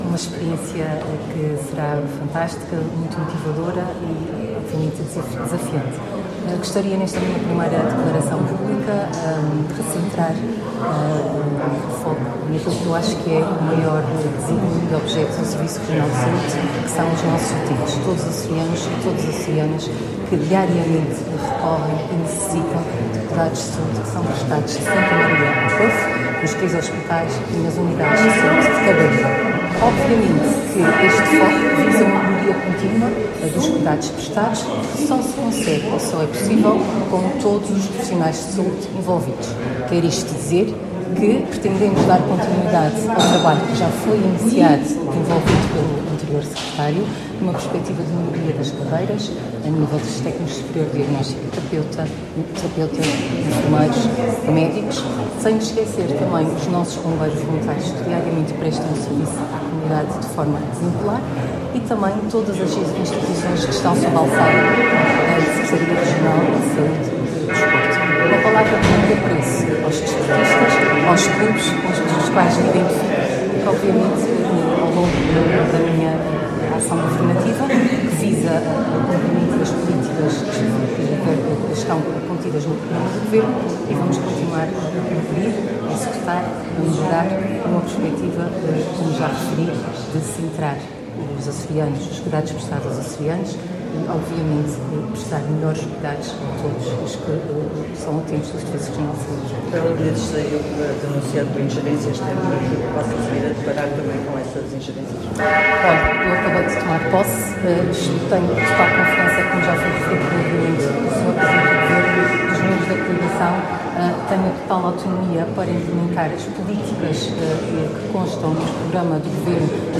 A antiga deputada ao Parlamento dos Açores Mónica Seidi tomou esta tarde posse, na Assembleia Legislativa Regional, como nova Secretária Regional da Saúde e Desporto do XIII Governo Regional dos Açores.